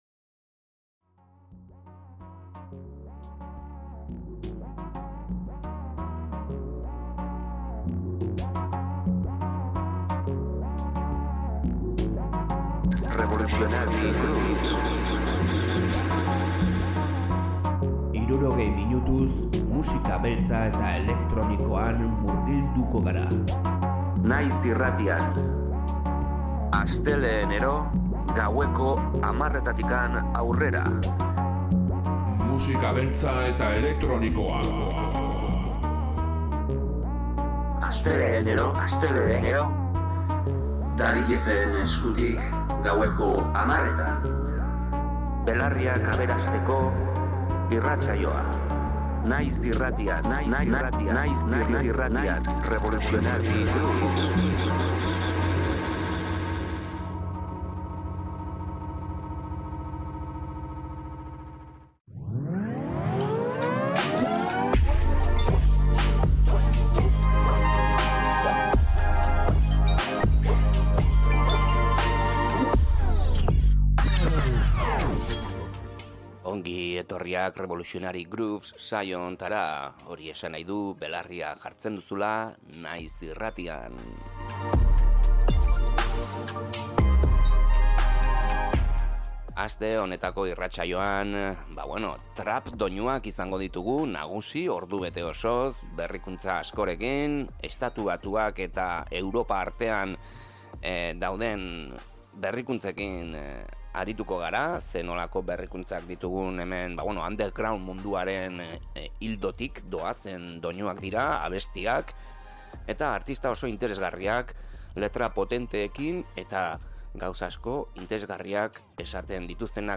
Naiz irratiko saio musikala, musika beltza eta elektronikoa jorratzen dituena